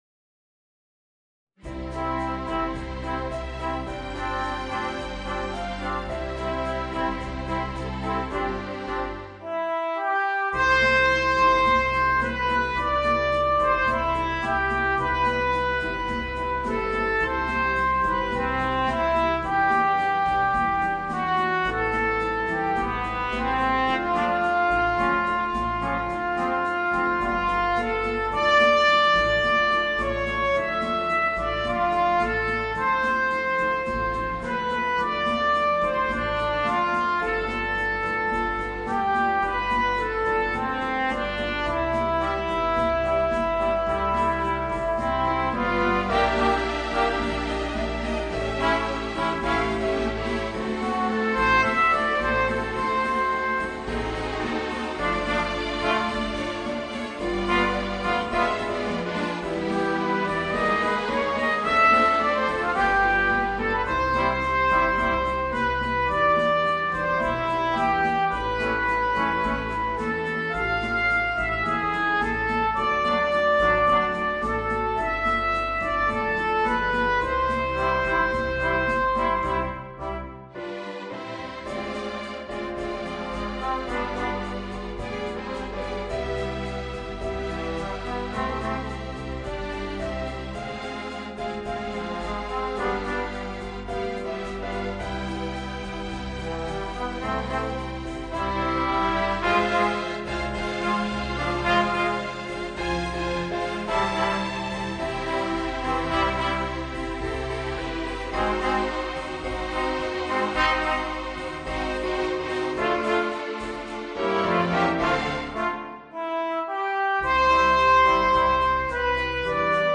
Voicing: 3 - Part Ensemble